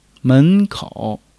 men2--kou3.mp3